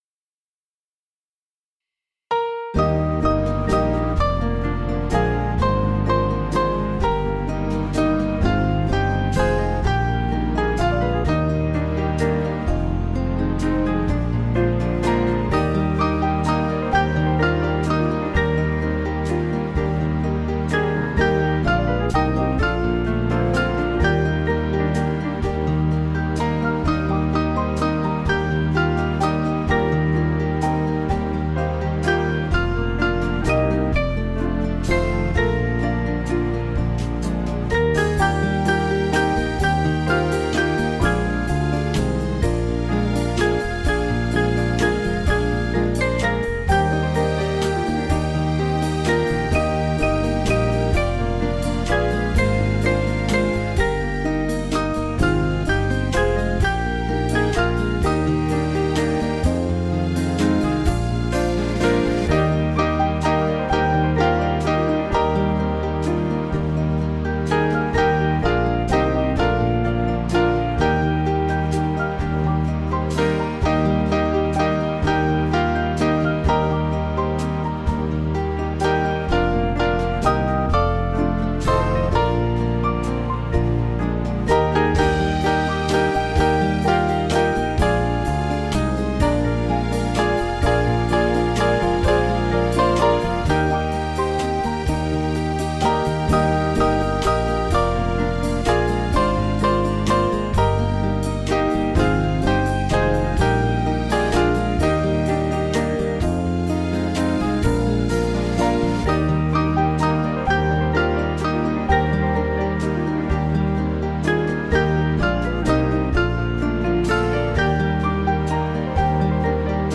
Small Band
(CM)   4/Eb 488.7kb